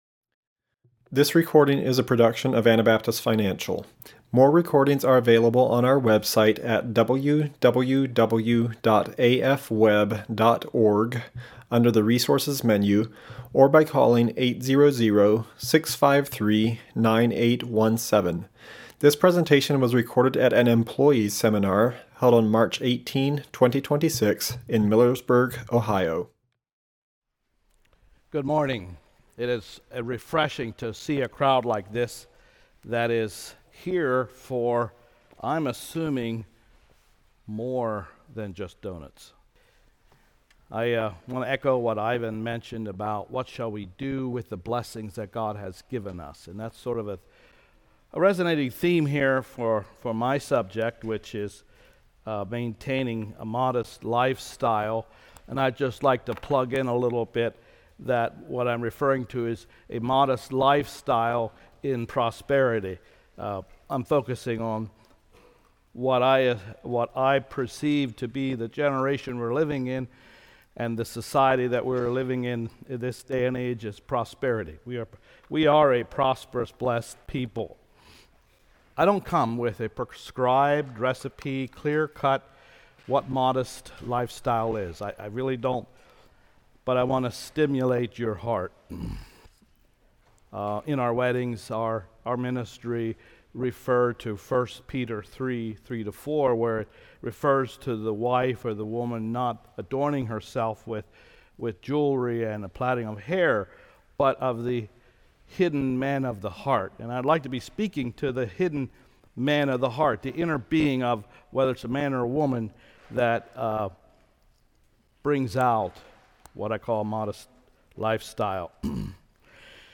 Ohio Employee Seminar 2026